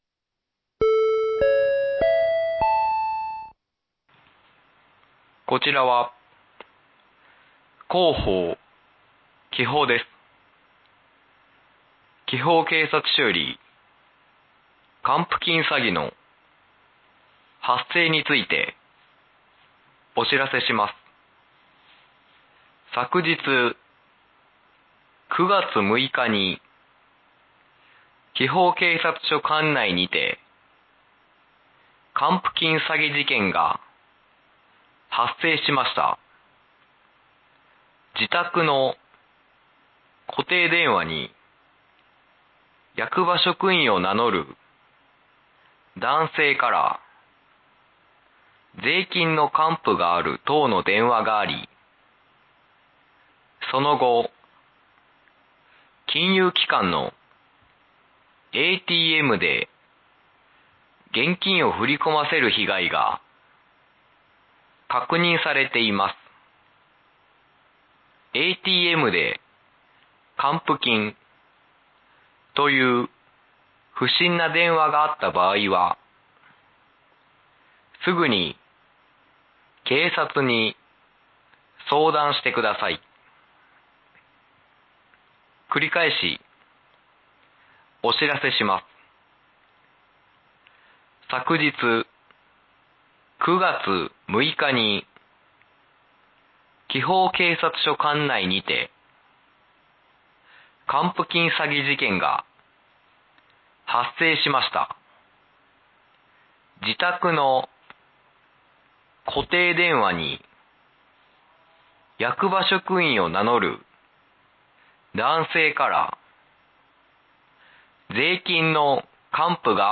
放送音声